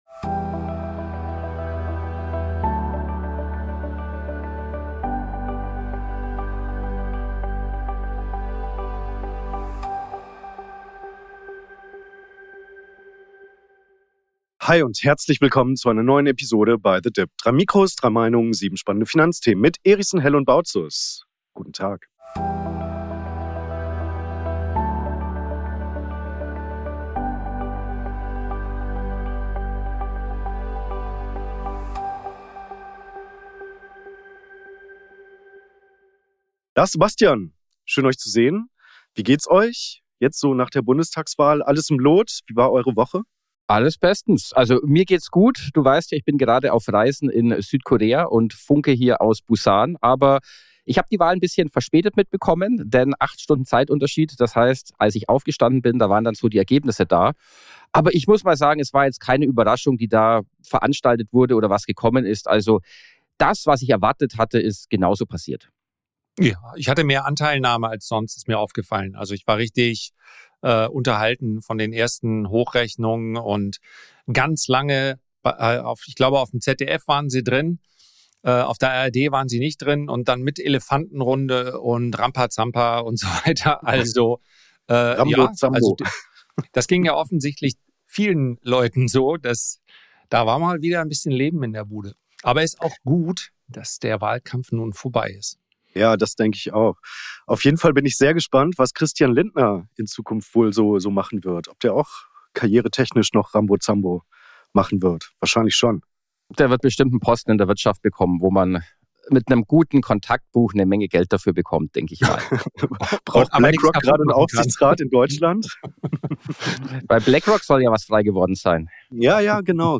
3 Mikrofone, 3 Meinungen